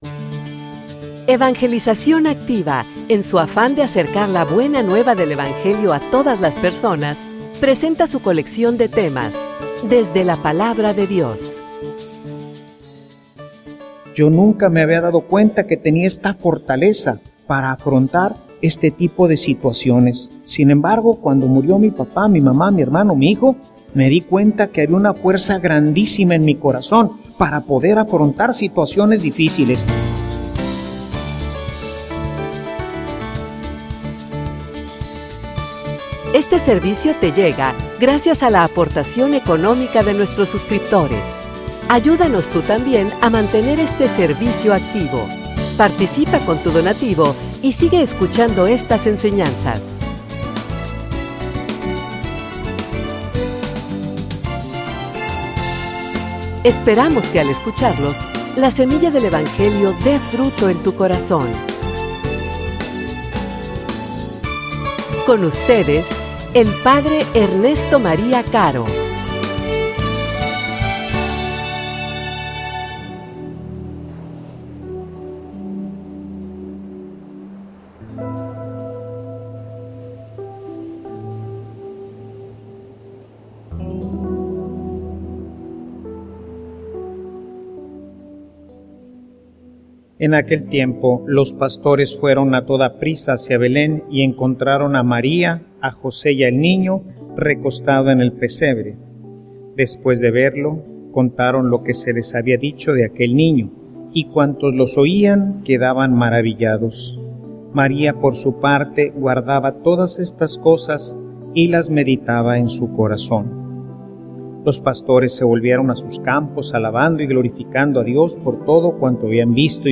homilia_Tomando_de_lo_nuevo_y_de_lo_antiguo.mp3